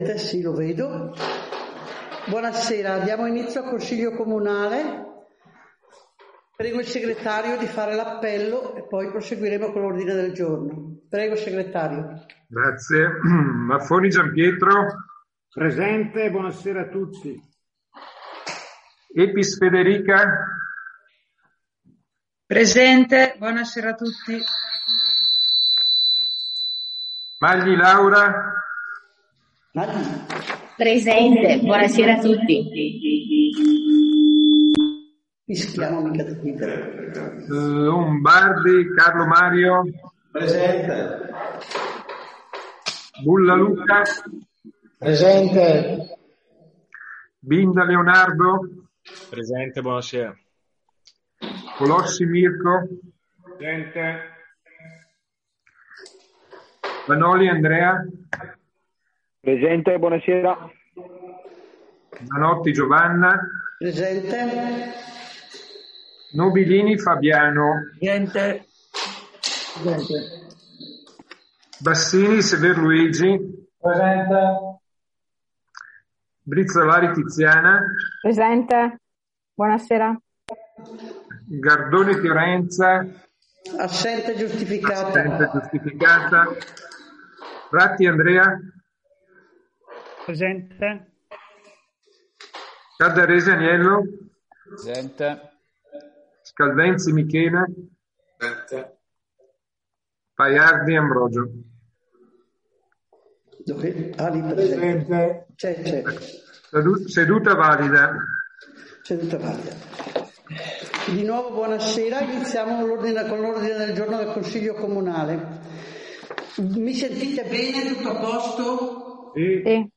Seduta Consiglio Comunale del 29 marzo 2021